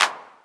013_CLAP ACCENT.wav